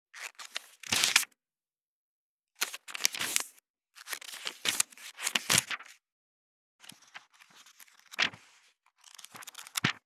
3.本ページめくり【無料効果音】
ASMR効果音本をめくる
ASMR